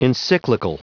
Prononciation du mot encyclical en anglais (fichier audio)
Prononciation du mot : encyclical